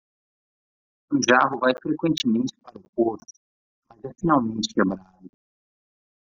Pronounced as (IPA) /ˈʒa.ʁu/